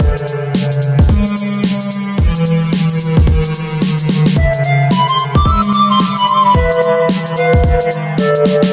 musical parts " Smooth Drum Loop 110bpm
描述：一个鼓点，以110 bpm循环播放
标签： 桶循环 110-BPM 鼓节拍
声道立体声